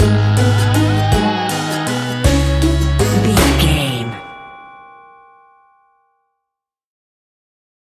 Aeolian/Minor
C#
ominous
eerie
acoustic guitar
percussion
strings
spooky